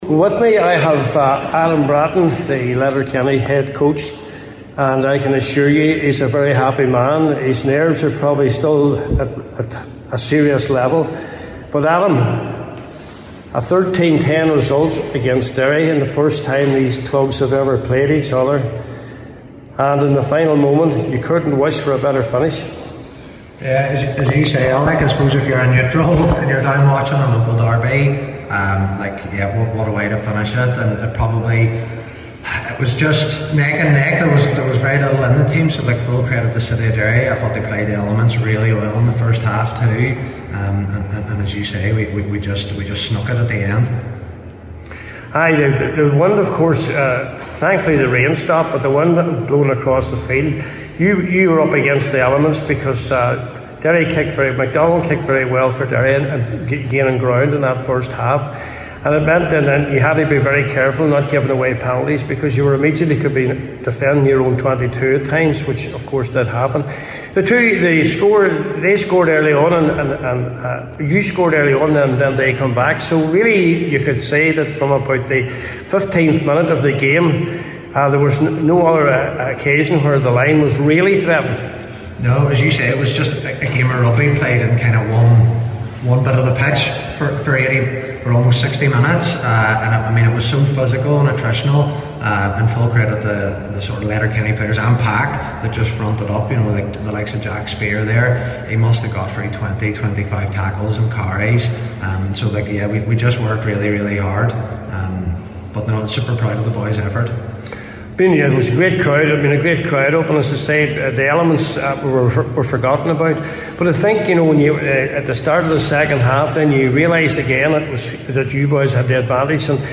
after today’s game…